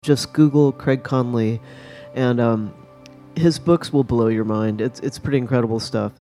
on Sonoma County KOWS FM Radio
Here's the complete radio show: You Are Here.